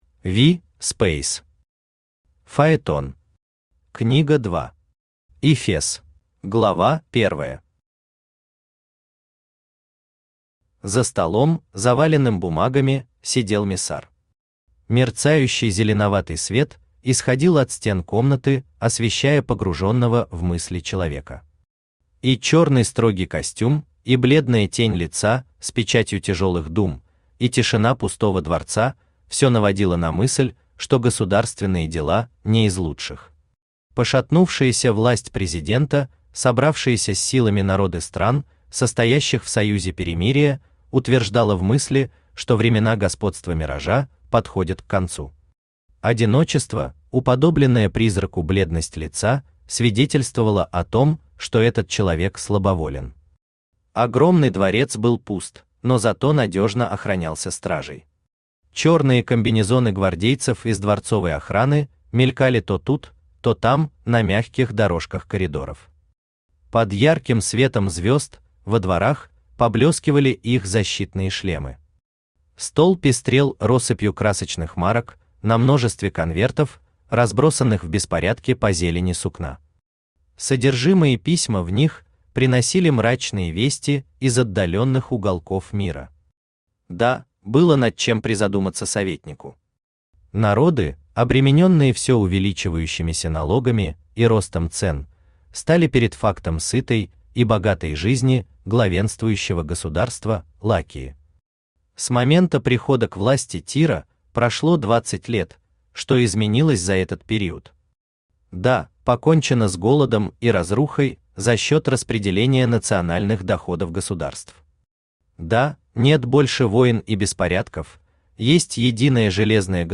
Эфес Автор V. Speys Читает аудиокнигу Авточтец ЛитРес.